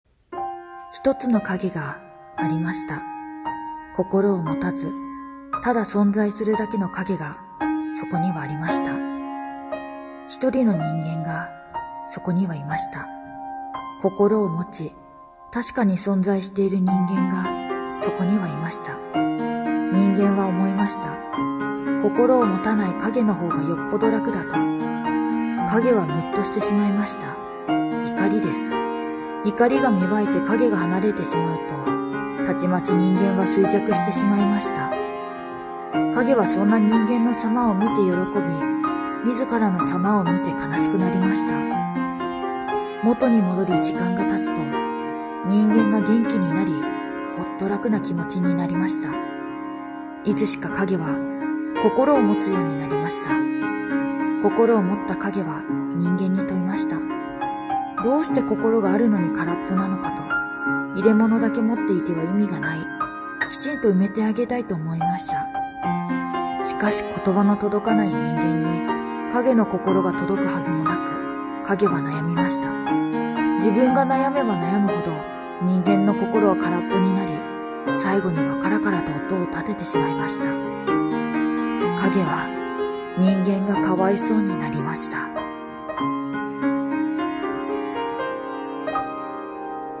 声劇【影】パート1